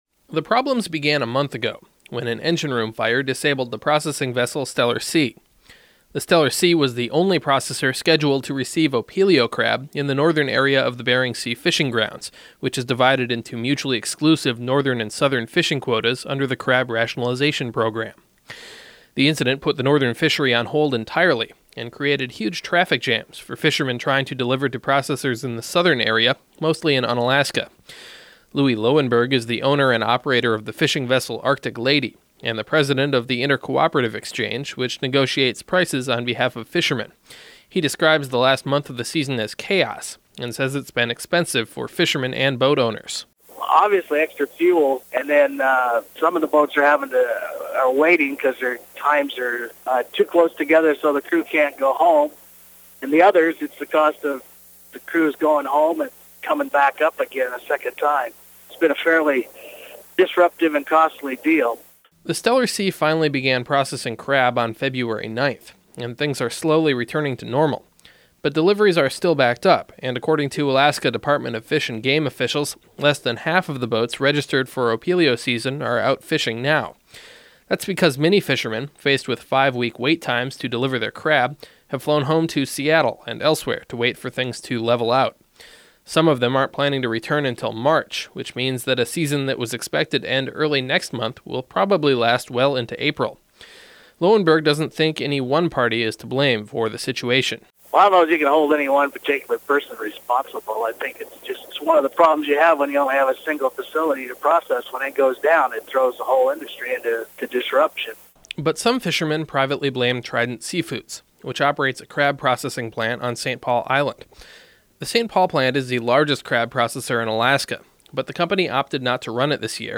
By KIAL News